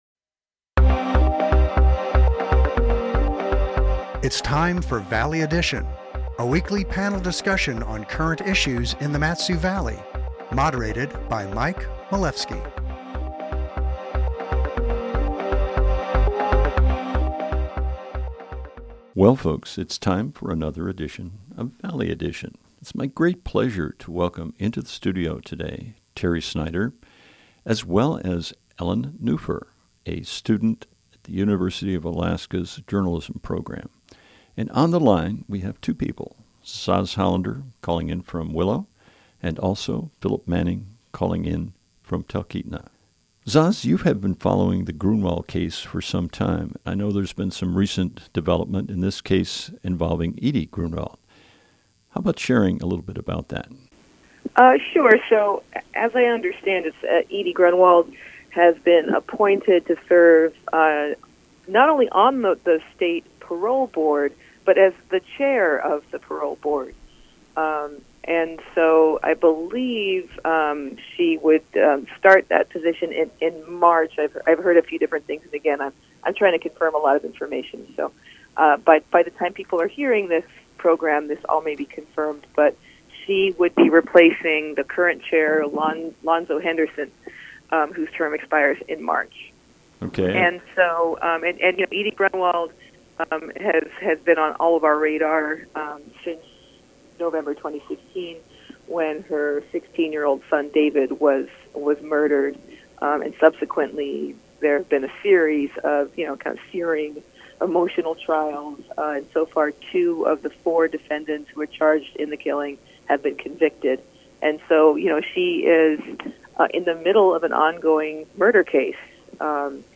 moderates a panel on current issues in the Valley